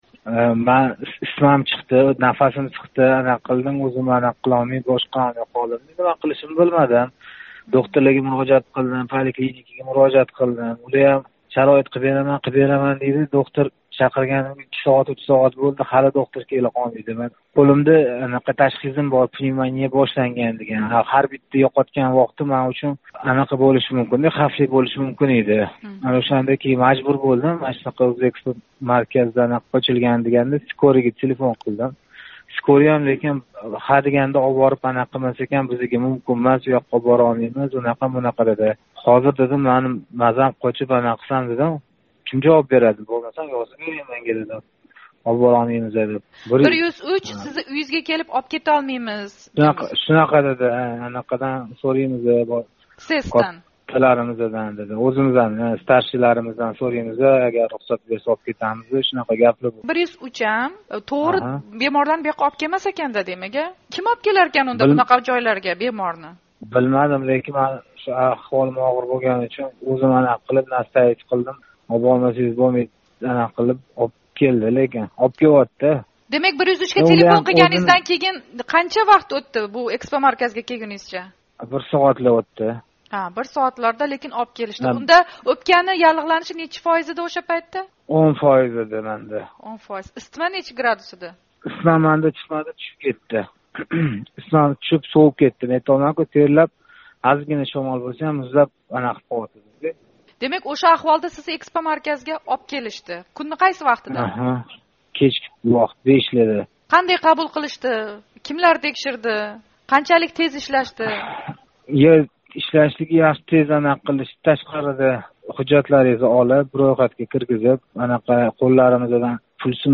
“Ўзэкспомарказ” биносида ётган бемор билан суҳбат